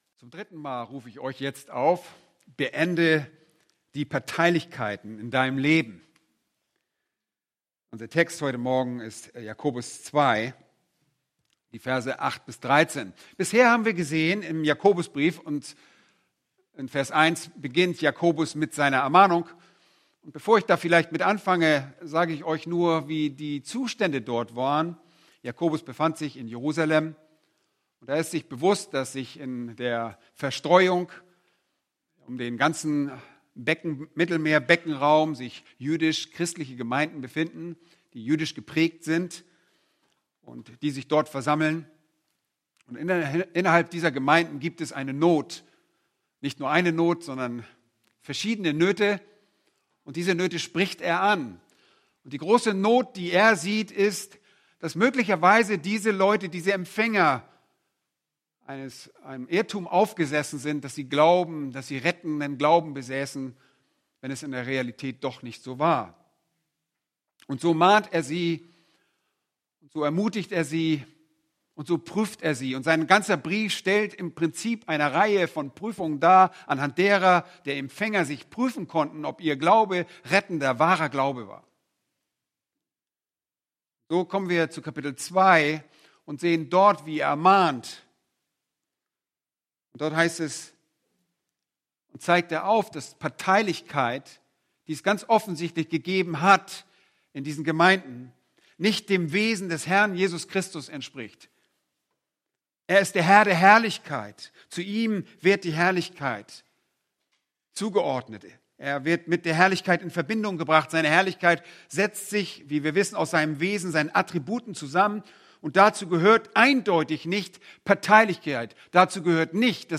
Predigten Übersicht nach Serien - Bibelgemeinde Barnim